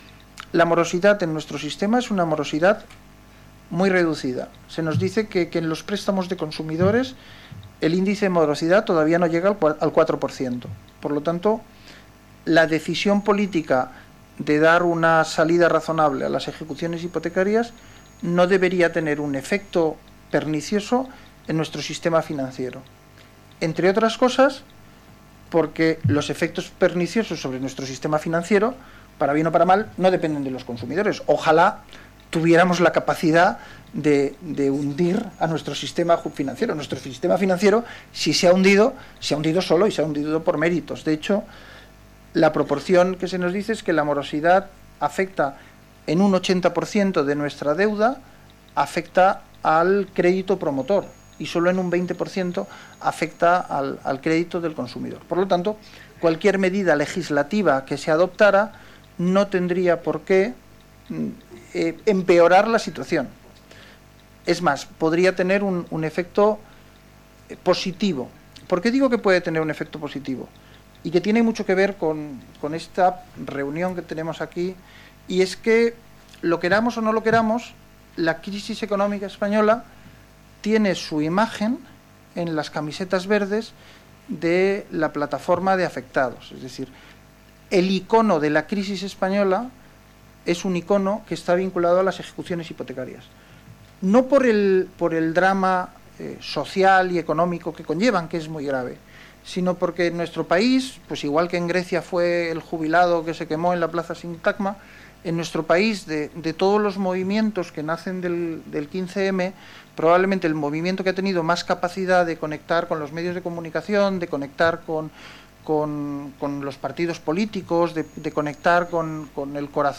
Juez Fernández Seijo. Invitado a la jornada sobre reformas hipotecarias organizadas por el Grupo Parlamentario Socialista 13/03/2013